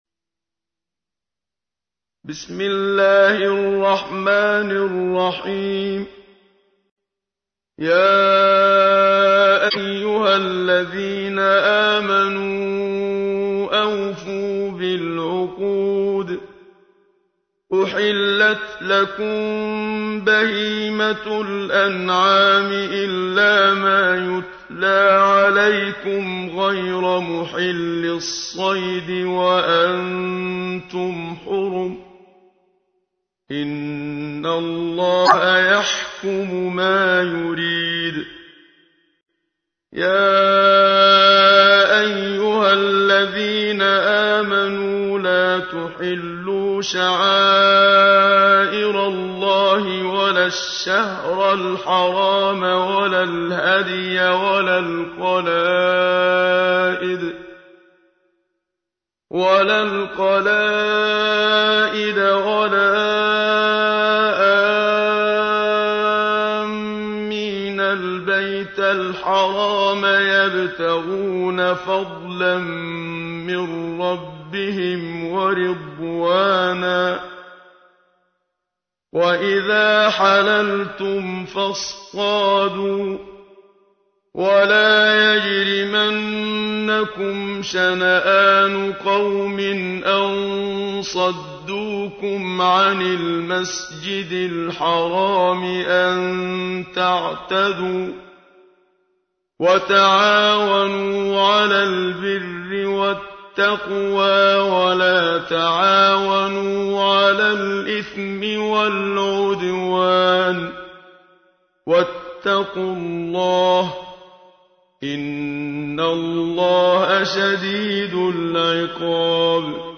تحميل : 5. سورة المائدة / القارئ محمد صديق المنشاوي / القرآن الكريم / موقع يا حسين